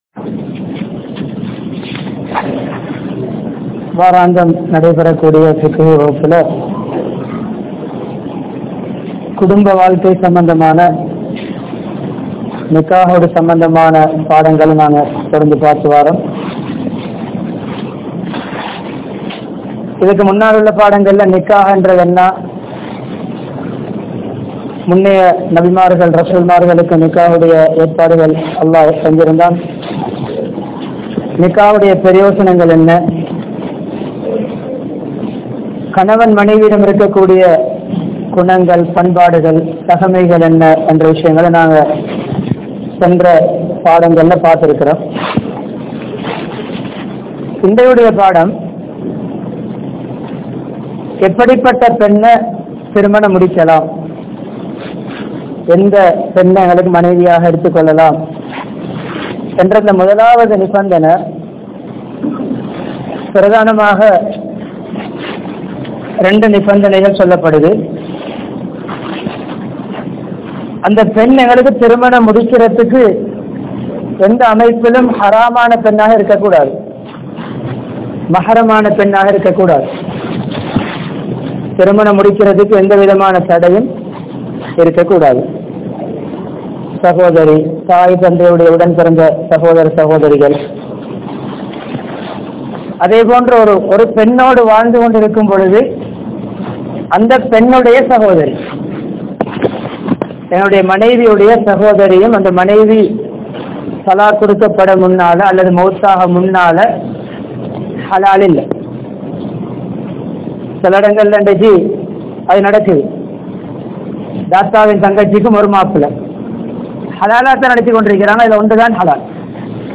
Kudumba Vaalkai (குடும்ப வாழ்க்கை) | Audio Bayans | All Ceylon Muslim Youth Community | Addalaichenai
Jamiul Falah Jumua Masjidh